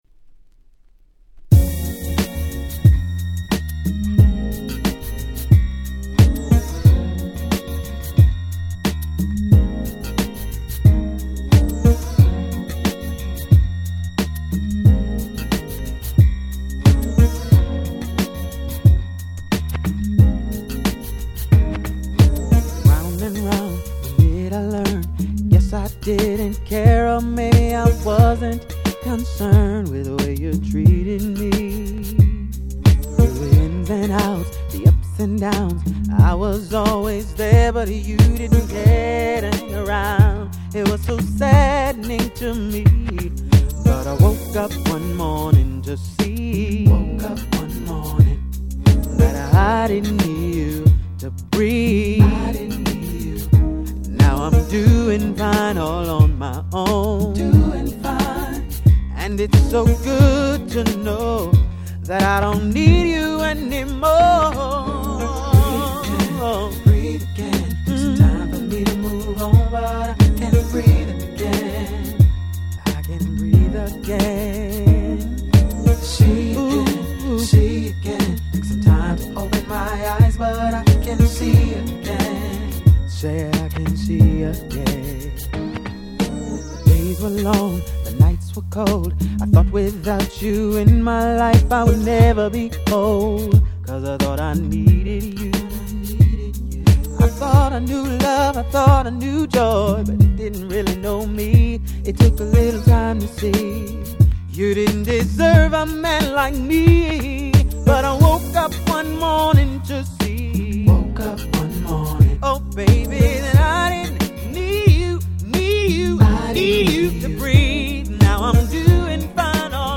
01' Nice Indie Soul/R&B !!
SmoothでGroovyな男性Vocal物で非常に良いです！